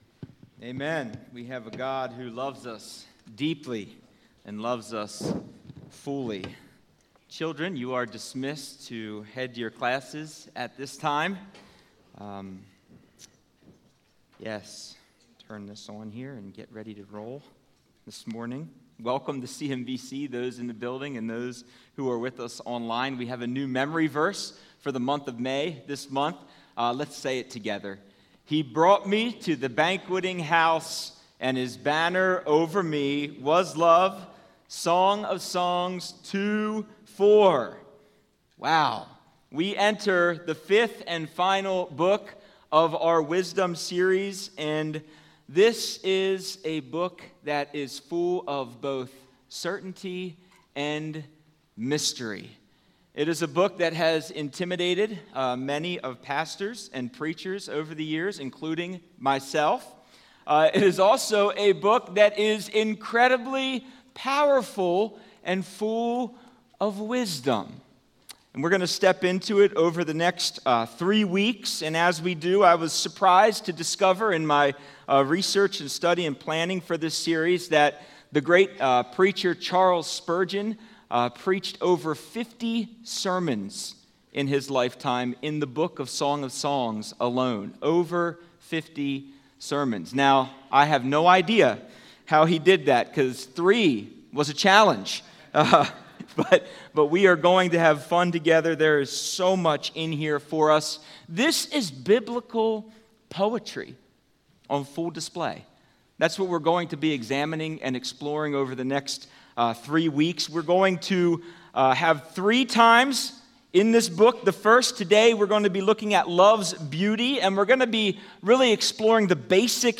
Song of Songs 1 Sermon